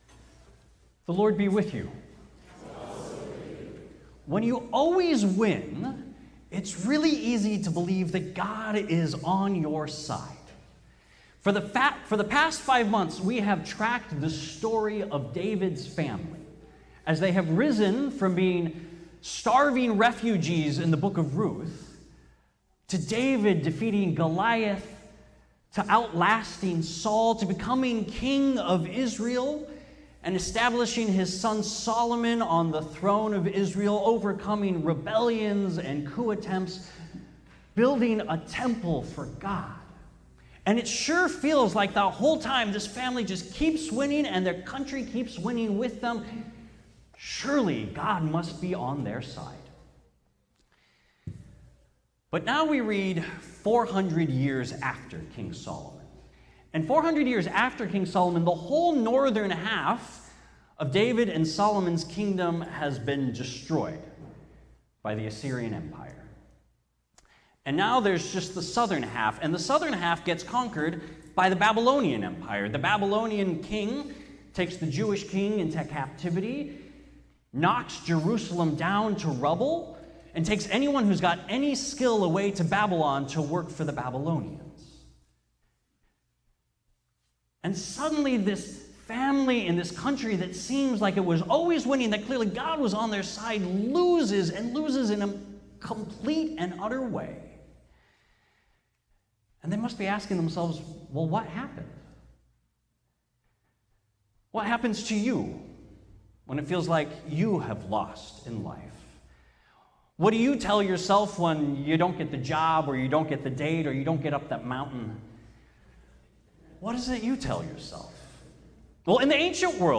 Sermon: God hasn't failed you because God hasn't finished with you.